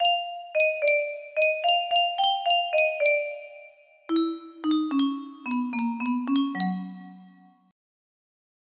Žádnej neví, co jsou Domažlice - ČD Znělky music box melody
Full range 60